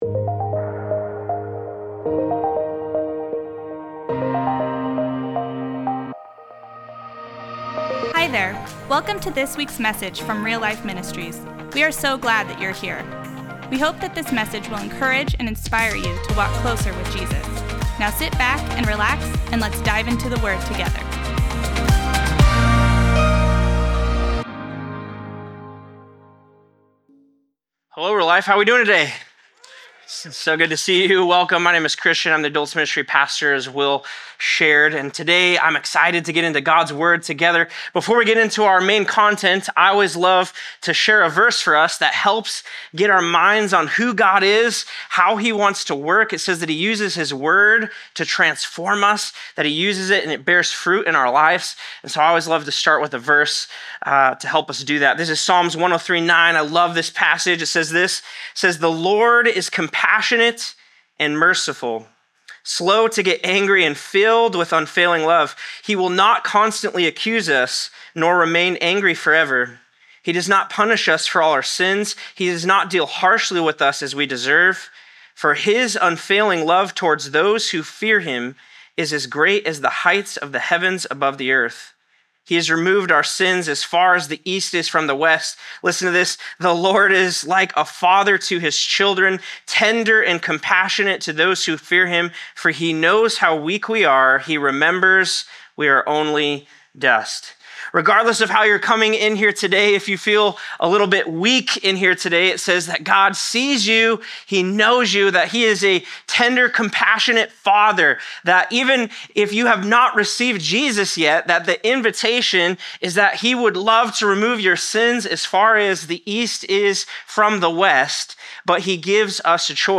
What Does it Mean to be a Son or Daughter in Christ? // CDA Campus
Sermon